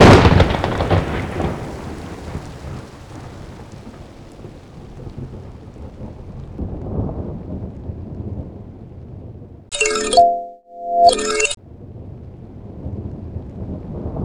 Index of /90_sSampleCDs/Optical Media International - Sonic Images Library/SI2_SI FX Vol 2/SI2_Gated FX 2
SI2 THUNDER.wav